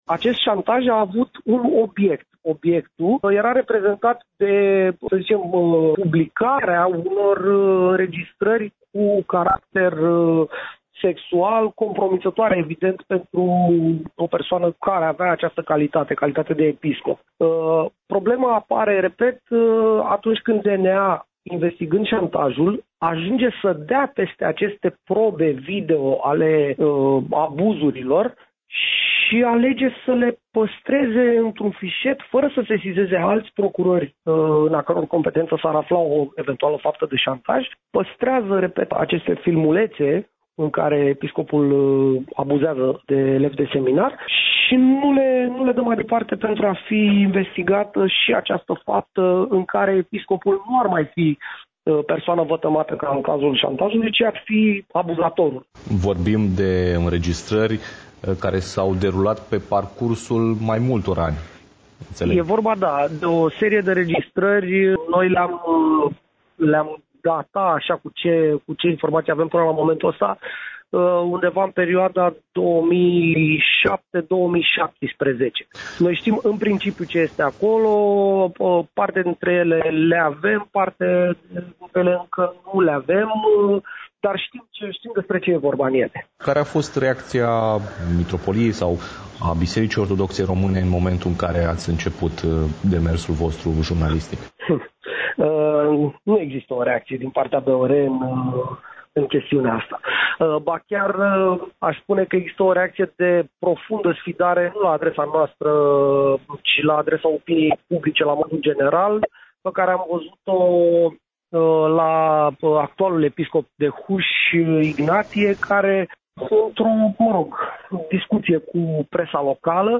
Într-o intervenţie la Radio Iaşi